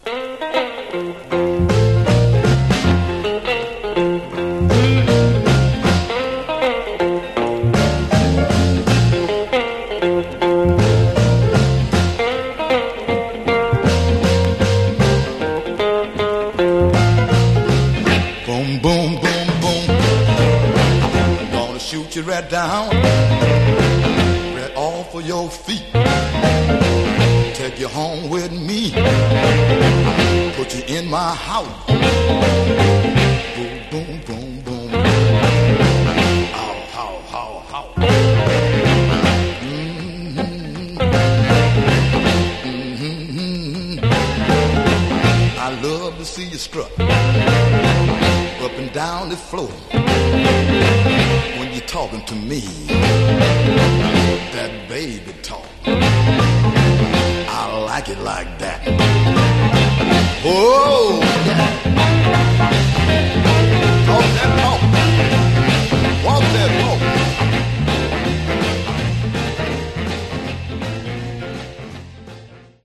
Blues track